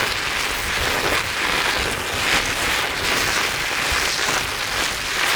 rubber_wheel_02.wav